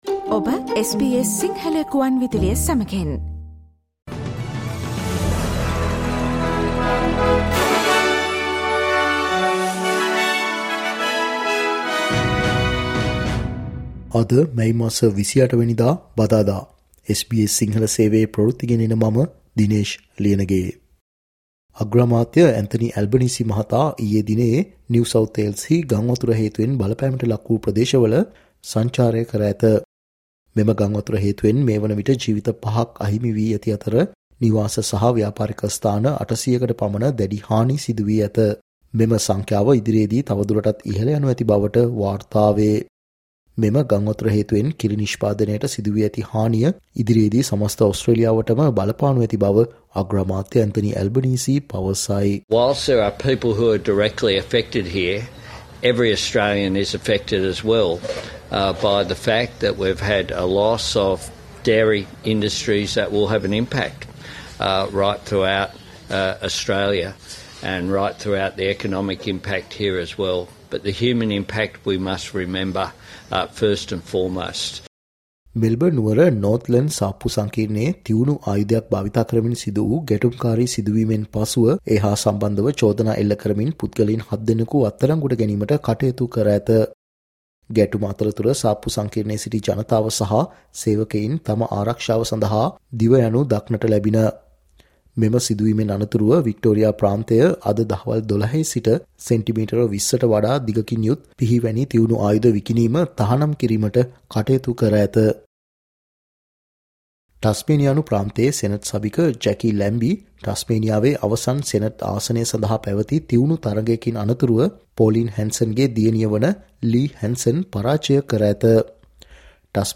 ඕස්ට්‍රේලියාවේ පුවත් සිංහලෙන් දැනගන්න, මැයි මස 28 වනදා SBS සිංහල Newsflash වලට සවන්දෙන්න